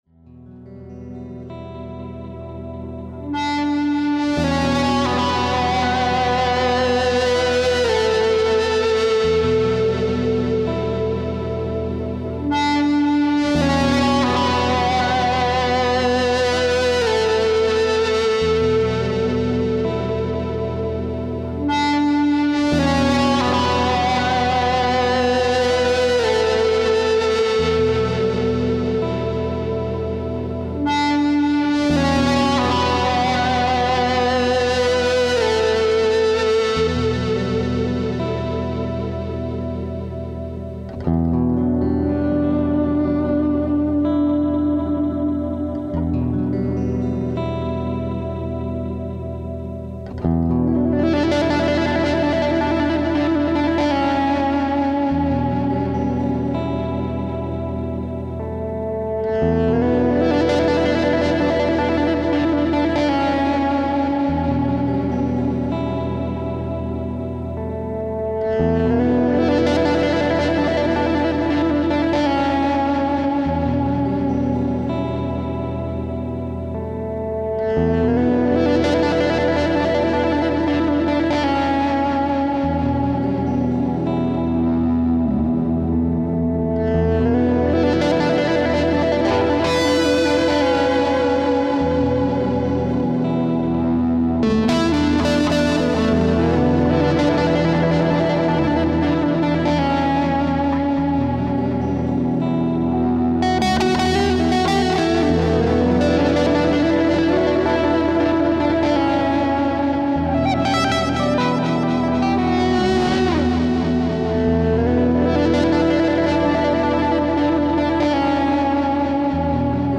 "Reading is Funktamental" is a monthly one-hour show about great books written about music and music-makers.
Expect lively conversation and a playlist of great music to go with it.